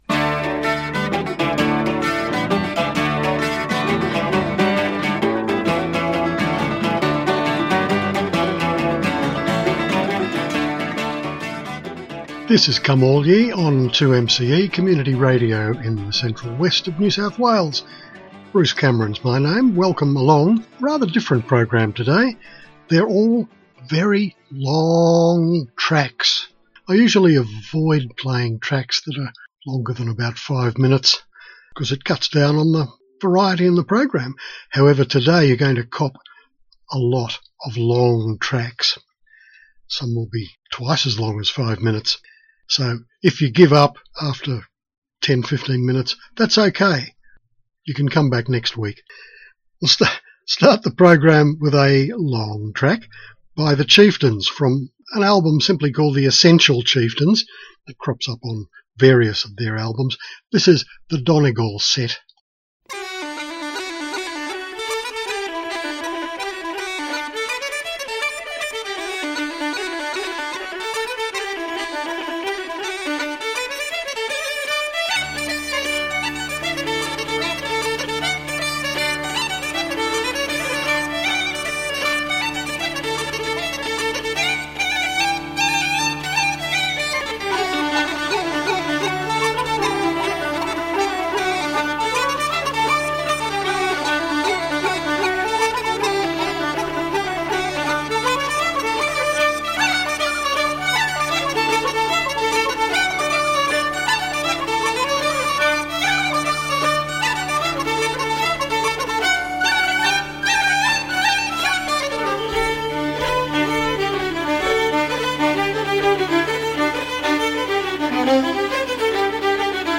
There was no featured album in the program today. I took the opportunity to play a bunch of interesting tracks usually considered too long for the average playlist.